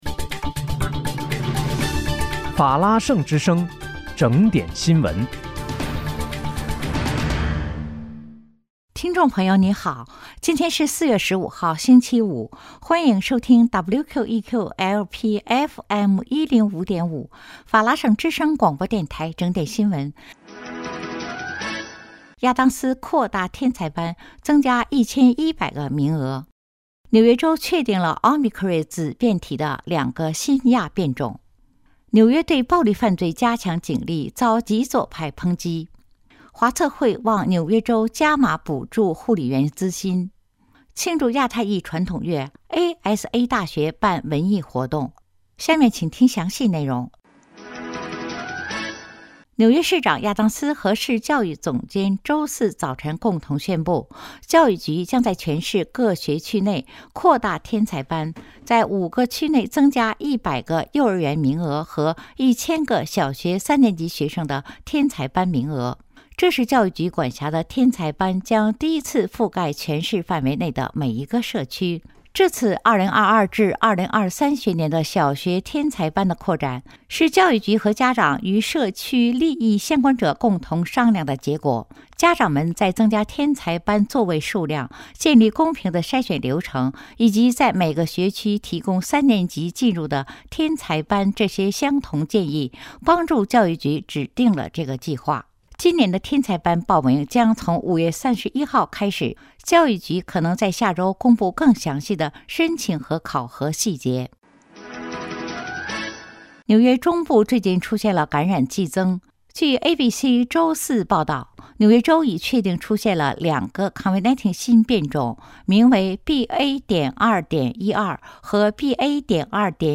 4月15日（星期五）纽约整点新闻